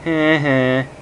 Heh Heh Sound Effect
heh-heh-1.mp3